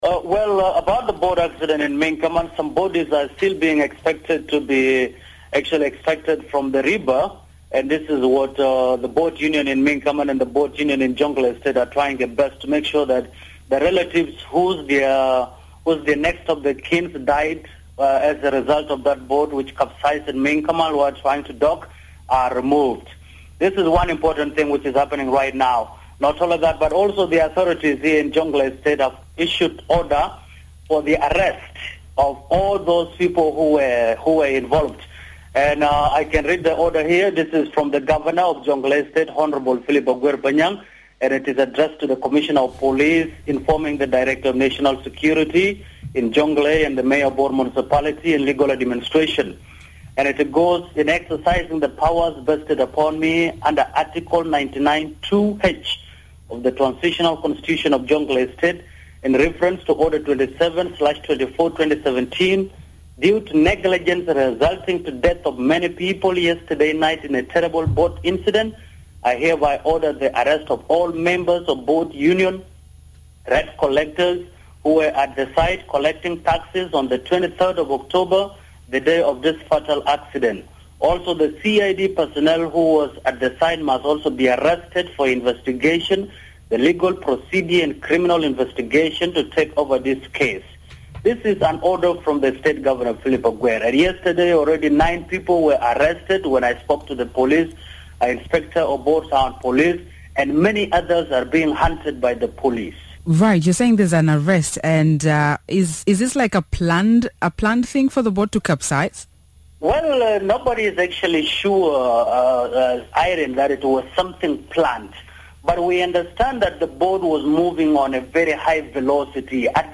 Reporter update about boat tragedy in South Sudan